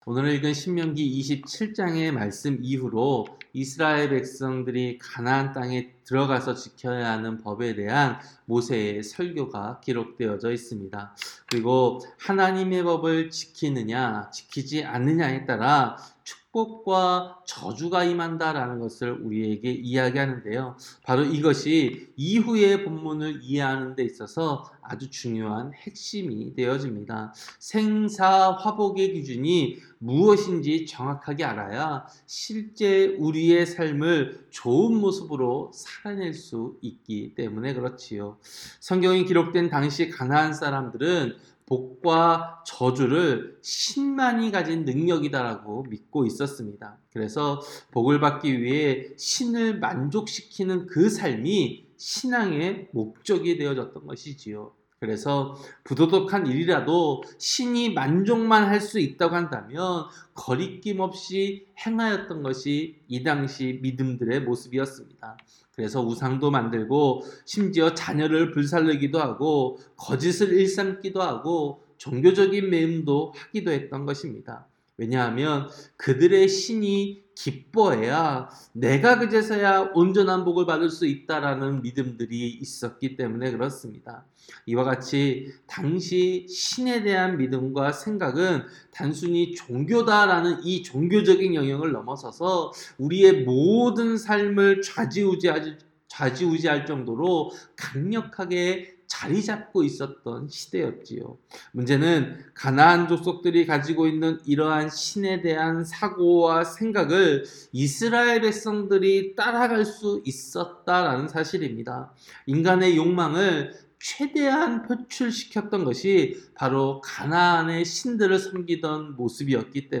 새벽설교-신명기 27장